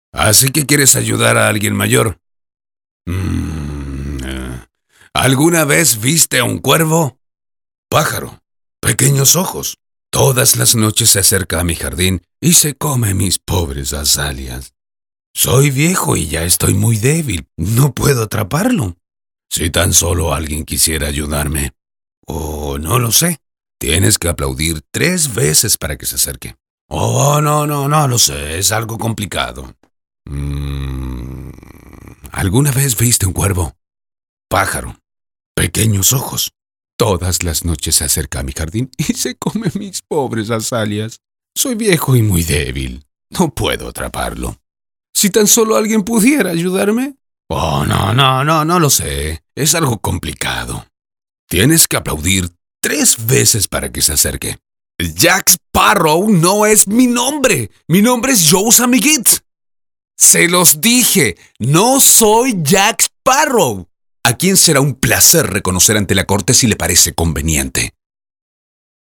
Male
Adult (30-50)
Audiobooks
Personaje Para Maqueta